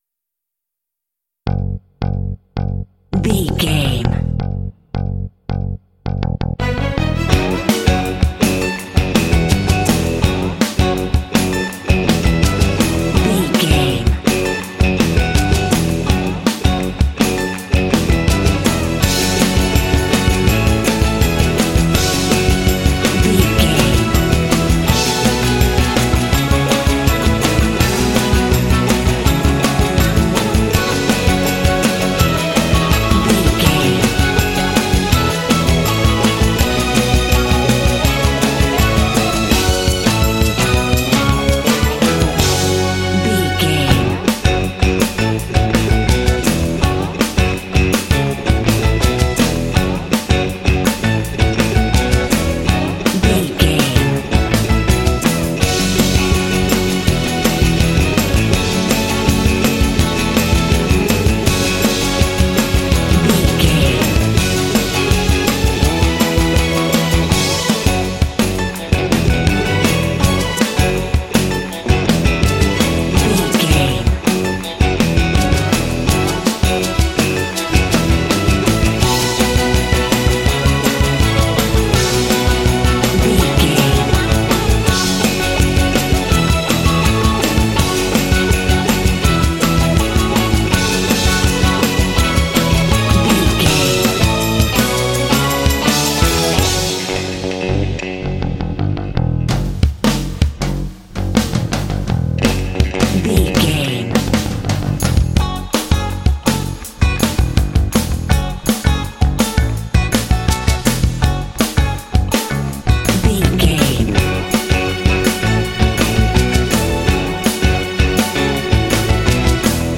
Cool, groovy funk track ideal for action games.
Dorian
F#
funky
smooth
driving
bass guitar
electric guitar
drums
strings
Funk
soul
motown